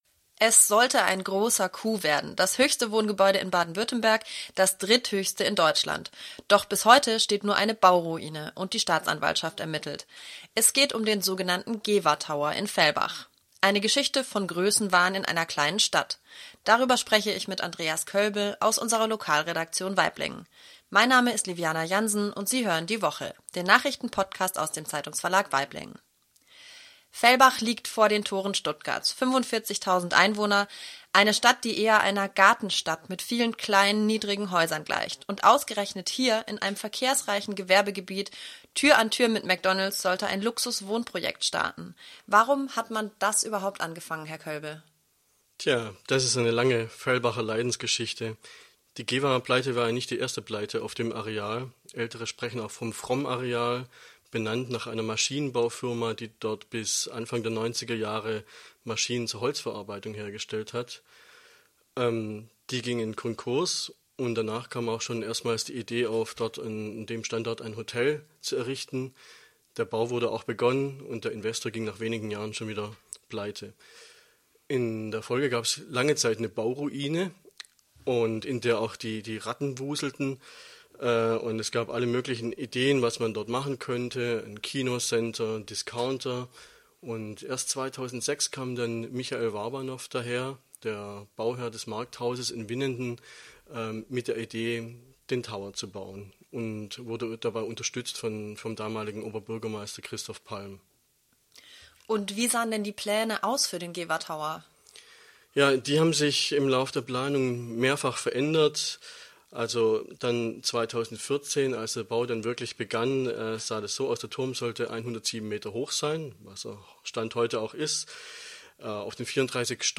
Die Woche ist der Nachrichten-Podcast aus dem Zeitungsverlag Waiblingen.